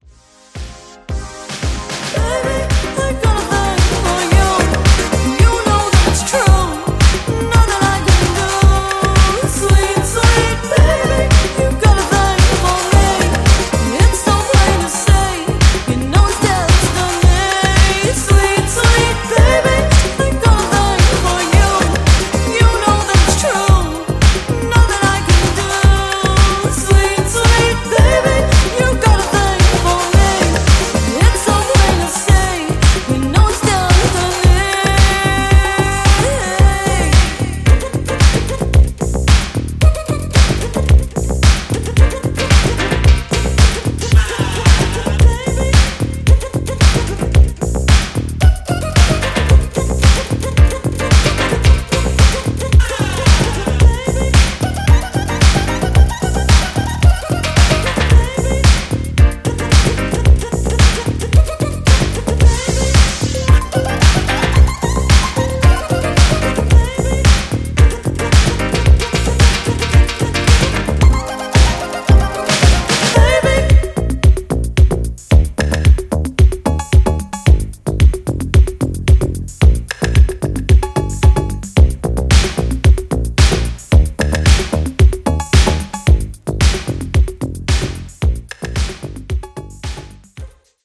ジャンル(スタイル) NU DISCO / DISCO / HOUSE / BALEARIC / EDITS